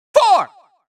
countIn4Far.wav